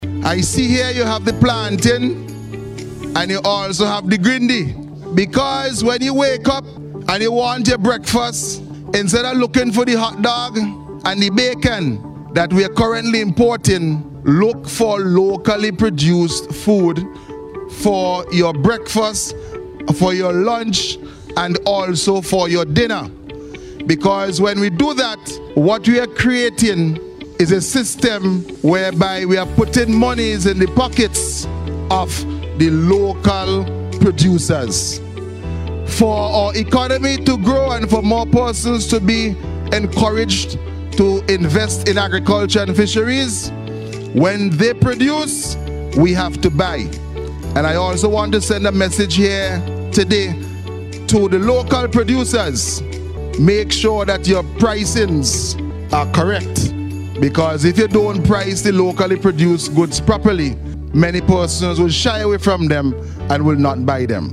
Delivering brief remarks at the handing over ceremony, Minister Caesar encouraged the students to eat more locally produced food to help to reduce the food import bill.